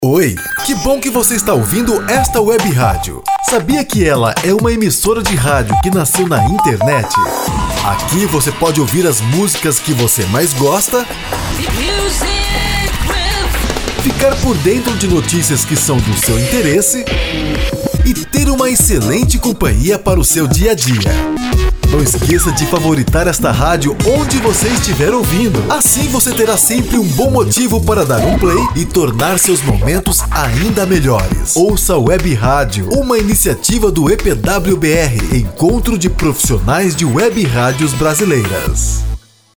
Neste vídeo você ganha um presente para sua Web Rádio, um SPOT comercial para ajudar a potencializar o engajamento de seus ouvintes.